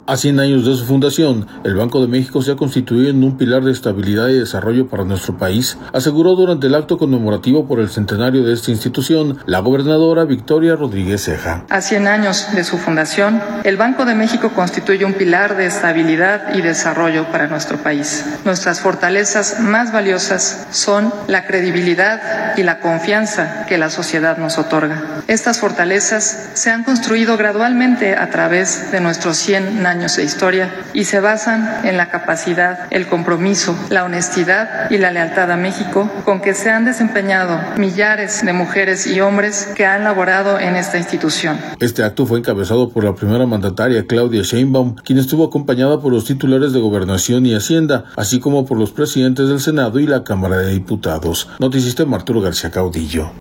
A cien años de su fundación, el Banco de México se ha constituido en un pilar de estabilidad y desarrollo para nuestro país, aseguró durante el acto conmemorativo por el Centenario de esta institución, la gobernadora Victoria Rodríguez Ceja.